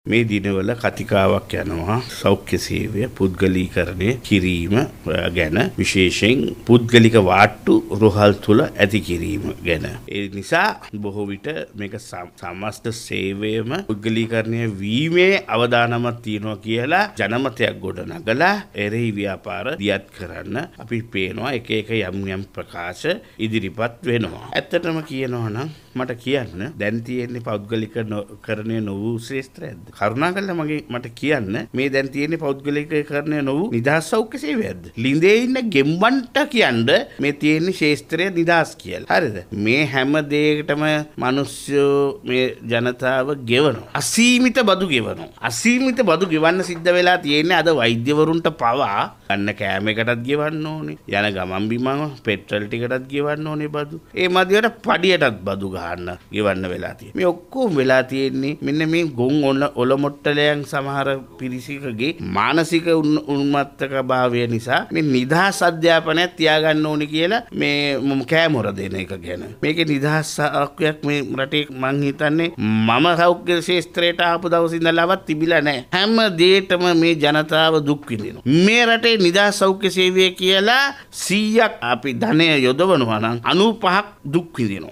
ඔහු මෙම දහස් දැක්වීම සිදු කළේ කොළොඹ පැවති මාධ්‍ය හමුවකට එක්වෙමින් .